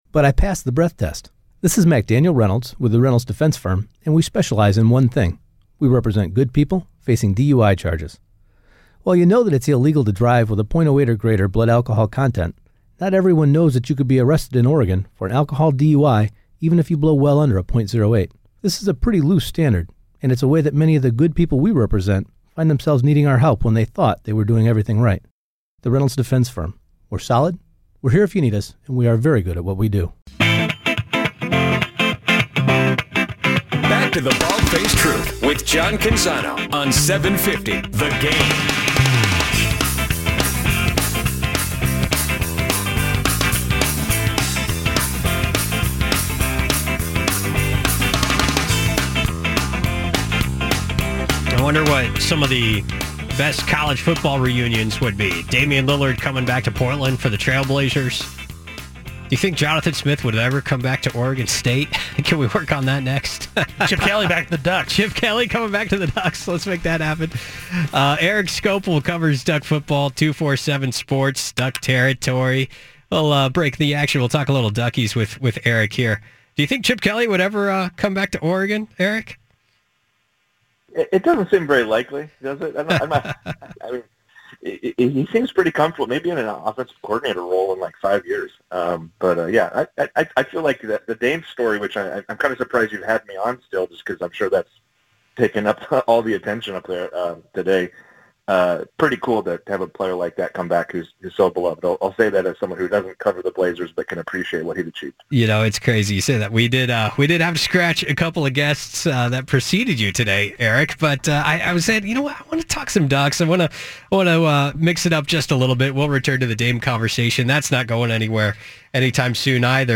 BFT Interview